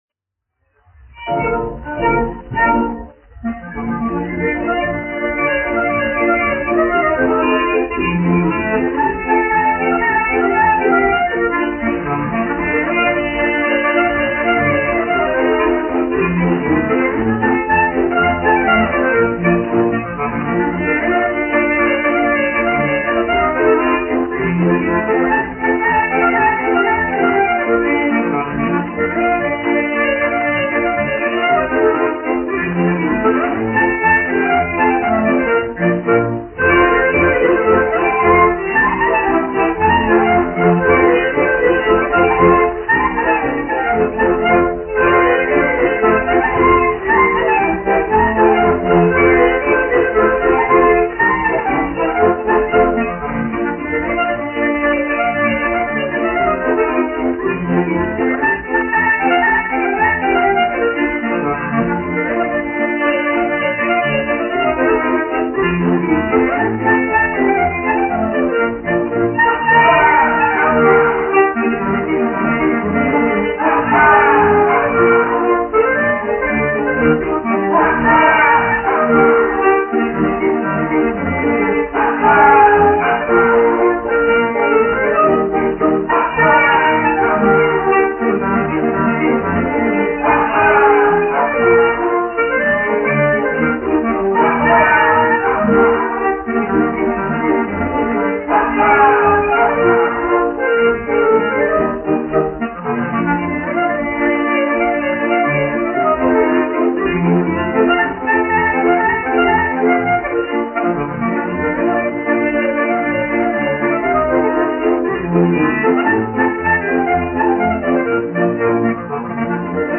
1 skpl. : analogs, 78 apgr/min, mono ; 25 cm
Polkas
Populārā instrumentālā mūzika
Skaņuplate